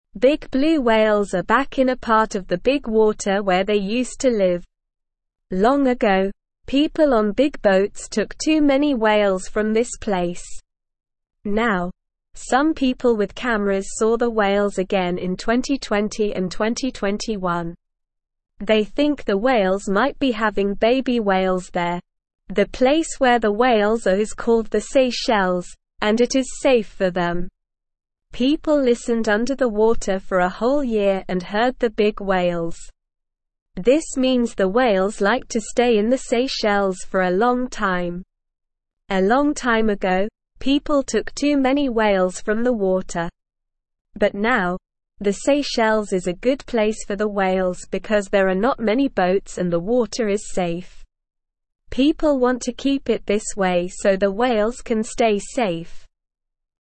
Slow
English-Newsroom-Beginner-SLOW-Reading-Blue-Whales-Return-to-the-Seychelles-a-Safe-Home.mp3